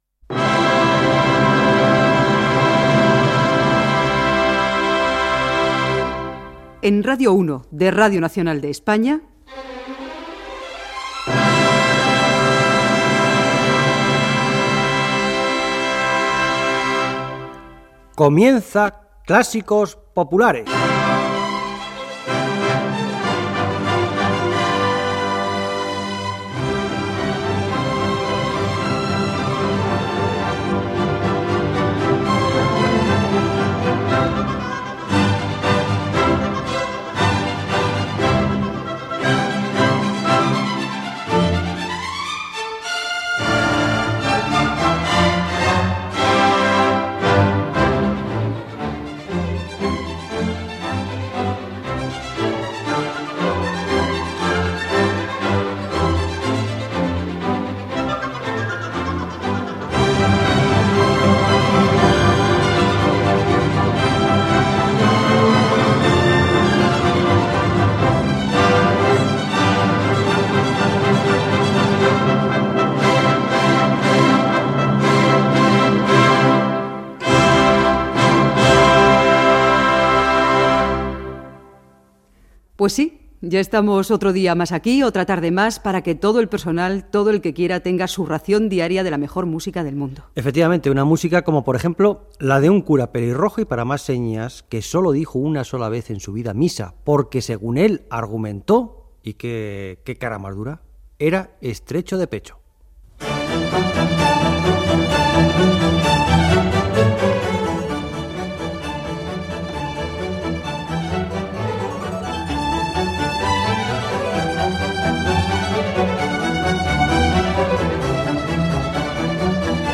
Presentació del programa i de dos temes musicals
Musical